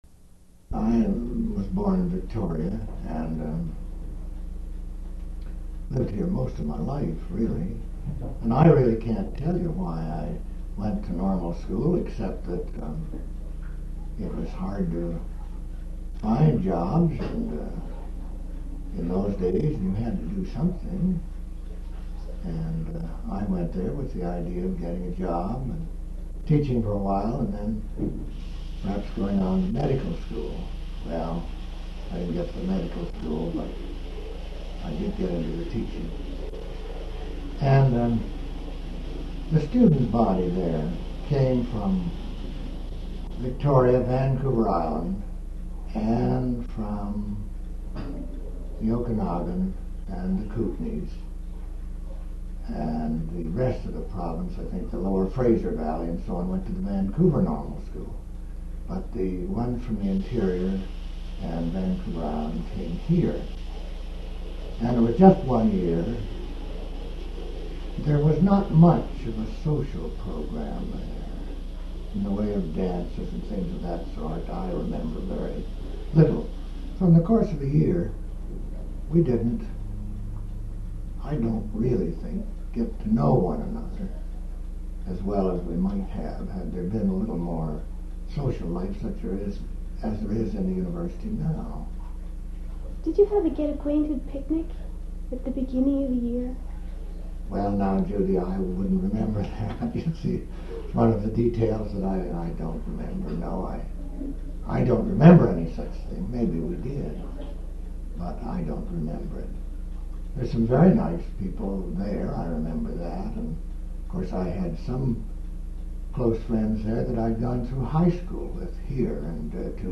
oral histories
Audio cassette copied in 1992.